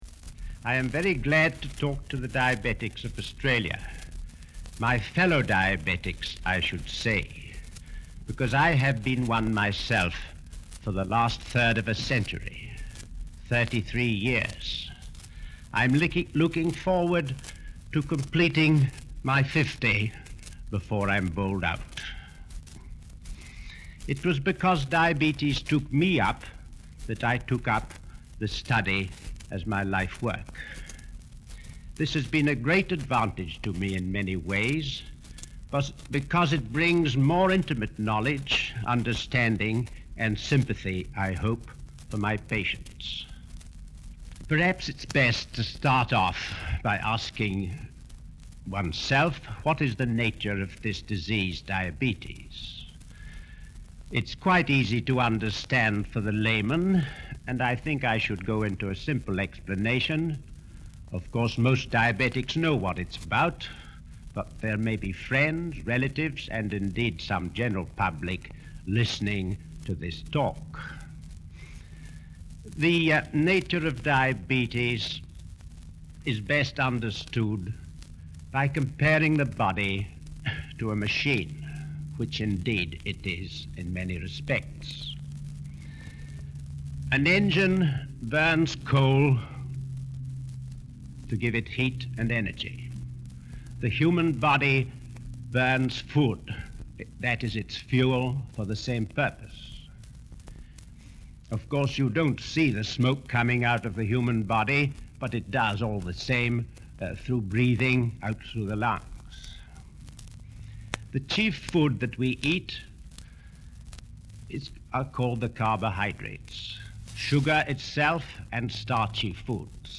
This is a recording of a speech made in 1953.